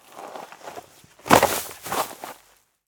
Garbage Bag Move Drop Sound
household